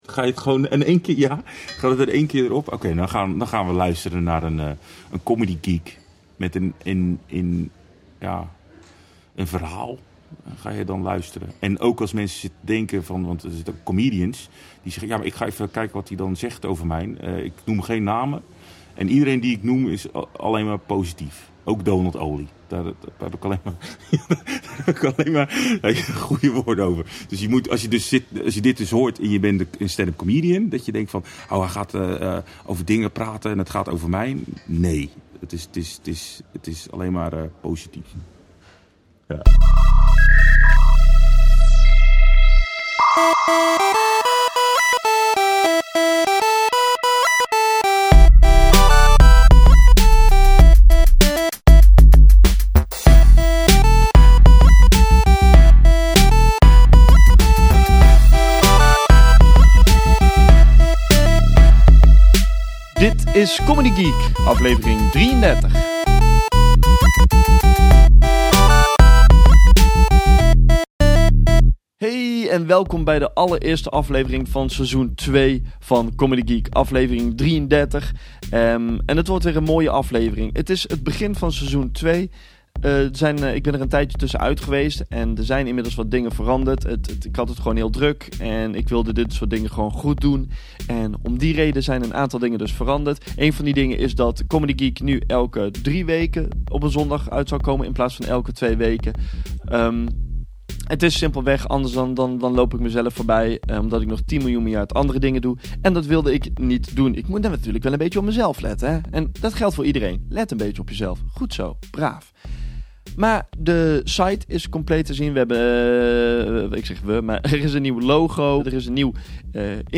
In deze podcast geen interviews, maar openhartige en eerlijke gesprekken aan de keukentafel bij de artiest thuis.